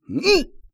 ZS格挡2.wav
人声采集素材/男3战士型/ZS格挡2.wav